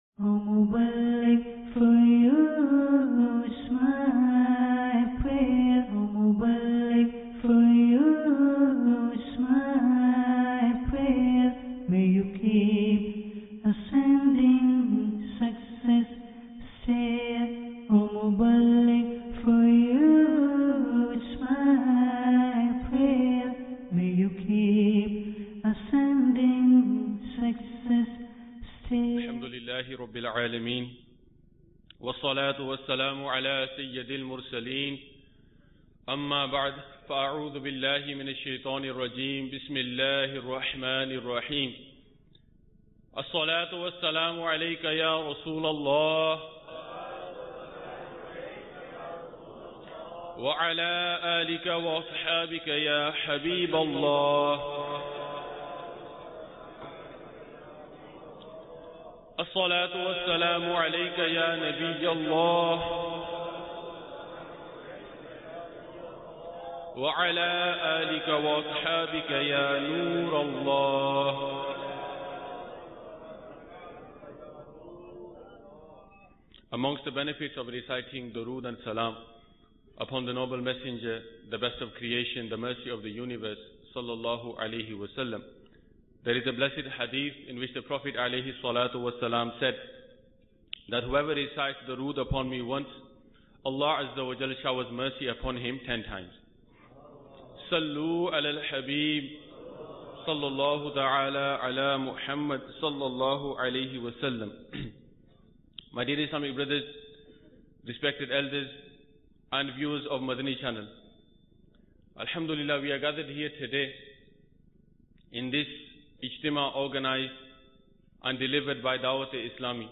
Sunnah Inspired Bayan Ep 256 - Excellence Of Parents Mar 27, 2017 MP3 MP4 MP3 Share In this video, the vocalist highlights the Status, Affection, Care and Excellence of Parents in his Sunnah Inspired Bayan. He also elaborates how children are treating their parents nowadays.